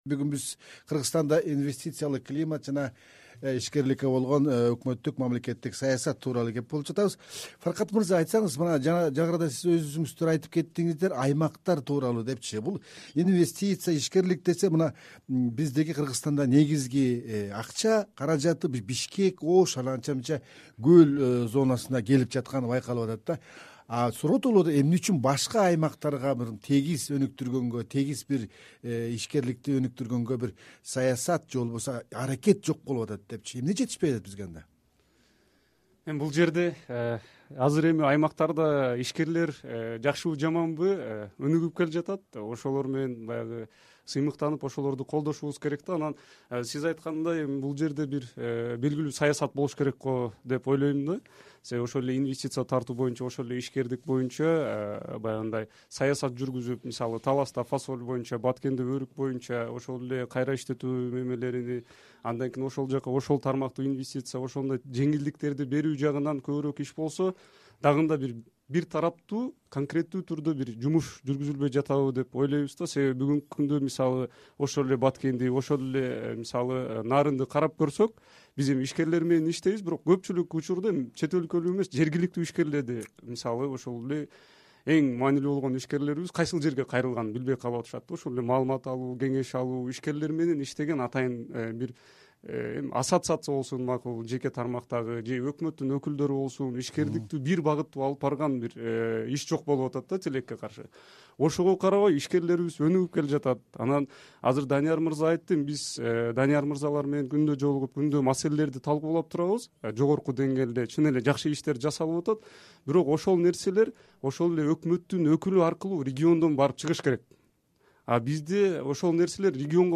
Кыргызстанда ишкерлерге болгон саясат өзгөрдүбү, салыктар, башка төлөмдөр боюнча жеңилдиктер барбы, эркин ишкерлик кылуу үчүн укуктук мүмкүнчүлүктөр түзүлгөнбү, сырттан келе турган жардамды жана чет элдик инвесторду күткөн көз карандылыктан качан кутулабыз? “Арай көз чарай” талкуусунда ушул суроолорго жооп издейбиз.